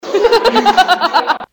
Play, download and share devil laugh carolina original sound button!!!!
devil-laugh-carolina.mp3